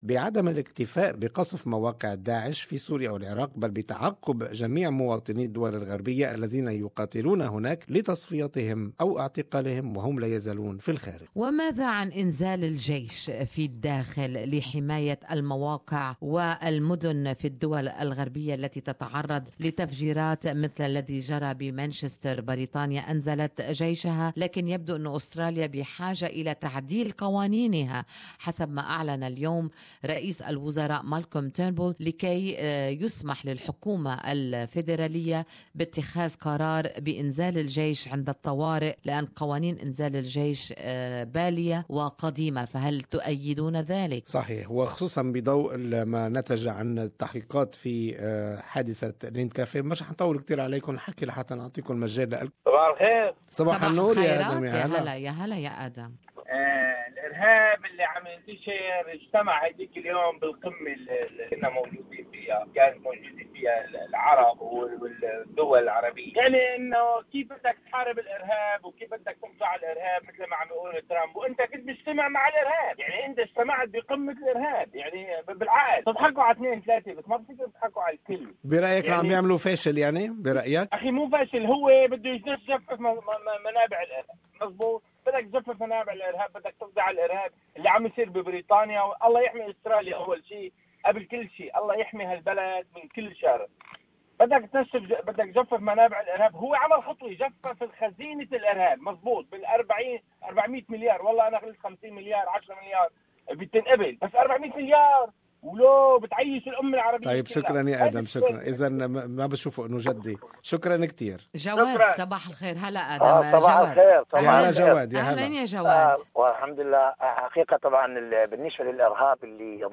SBS Arabic24 asked the listeners about their opinions on Eradicating Daesh in its homeland